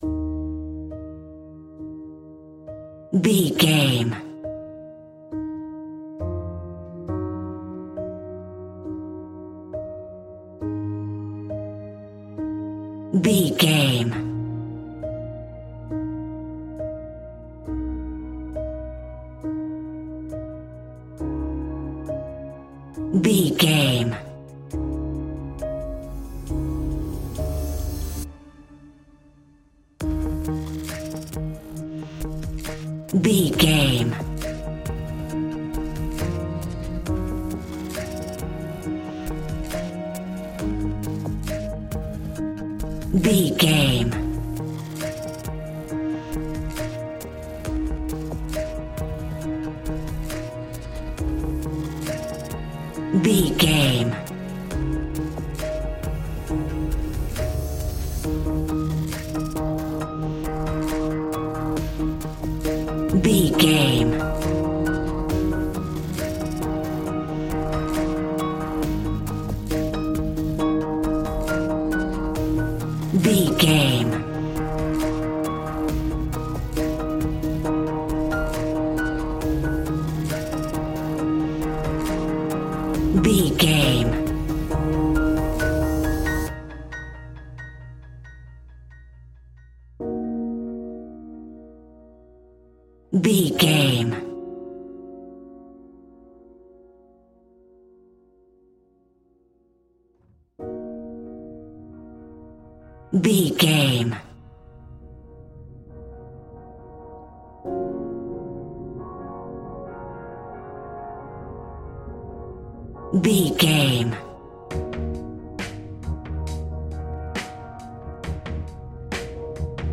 Ionian/Major
D♯
electronic
techno
trance
synths
synthwave
instrumentals